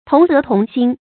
同德同心 tóng dé tóng xīn 成语解释 见“同心同德”。